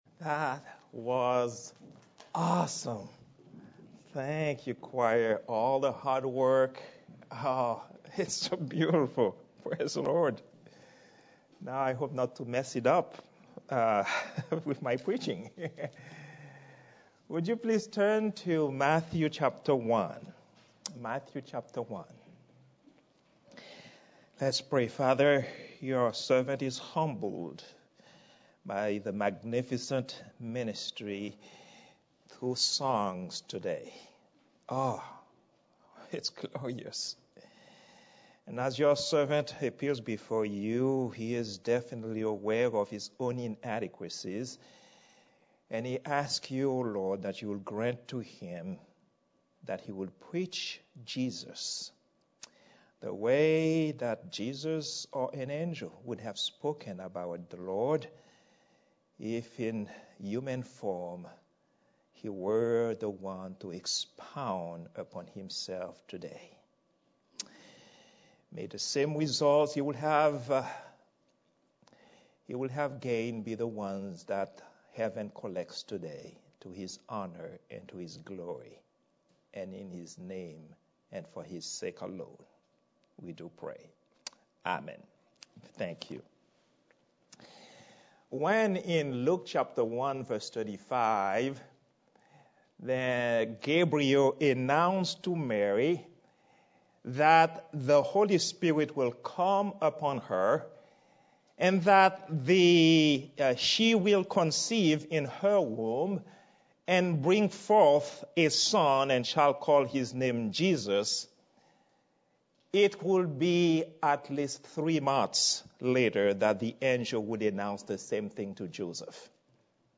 Love in the Church Sermon #5 - Beneficence of Love in the Church - Charity - Robinson Baptist